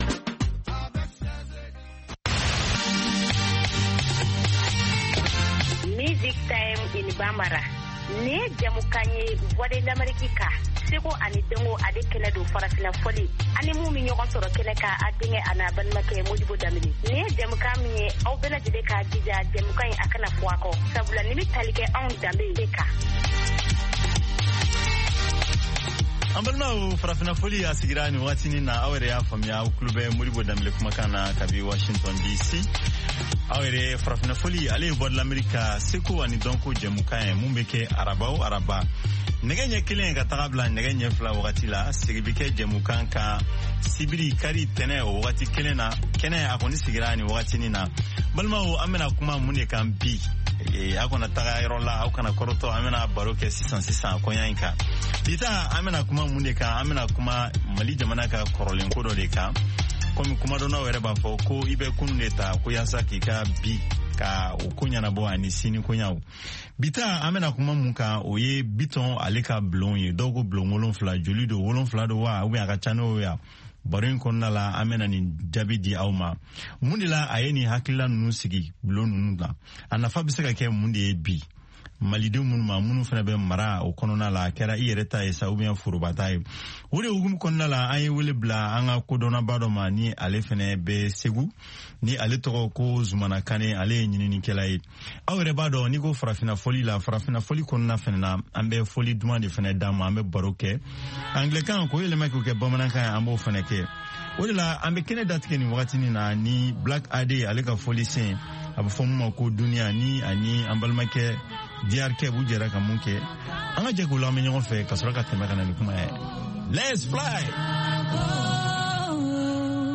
Bulletin d’information de 17 heures